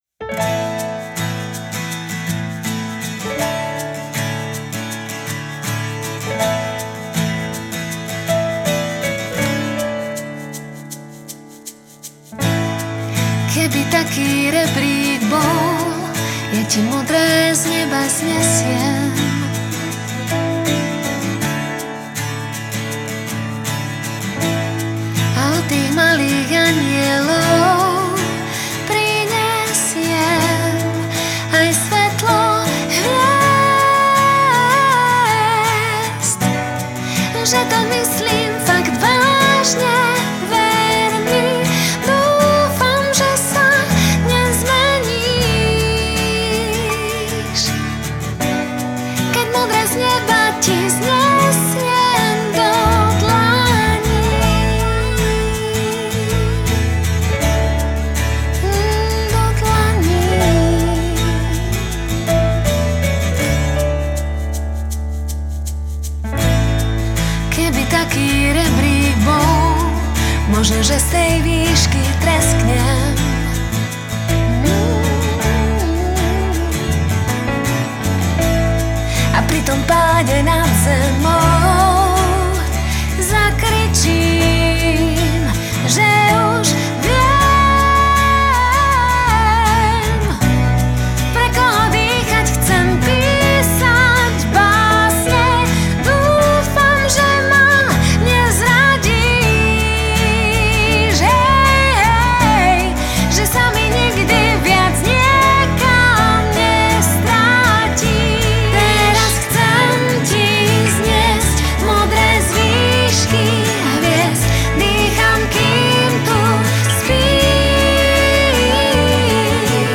gitary
basgitary
klávesy
bicie